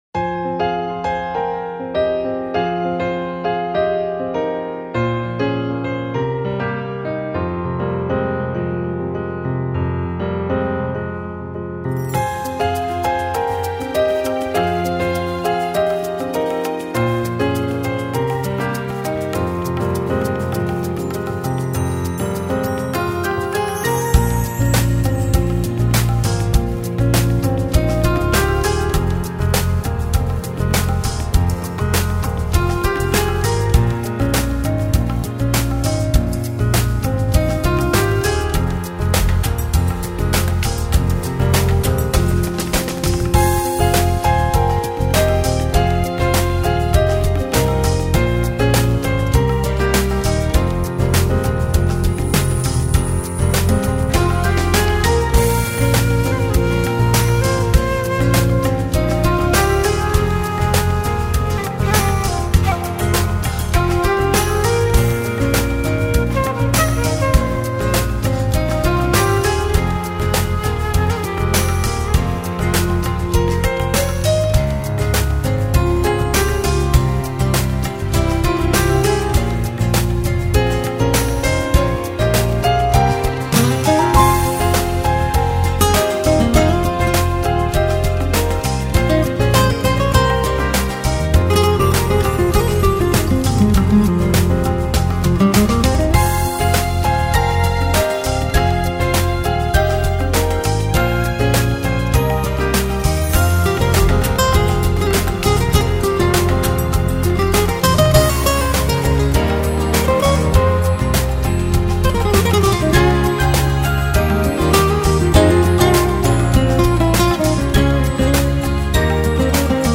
آهنگ بی کلامfree spirit